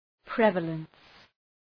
Προφορά
{‘prevələns}